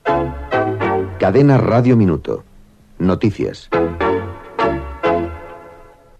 Careta dels serveis informatius